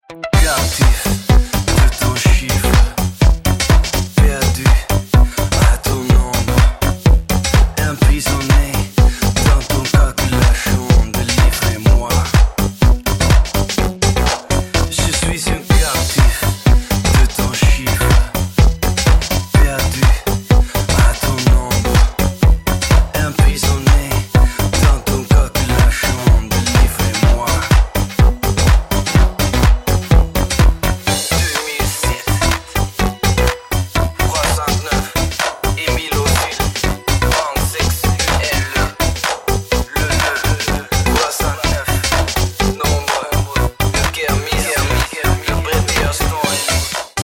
Lo-fi techno funk and fucked up house from Amsterdam.
dirty electronic funk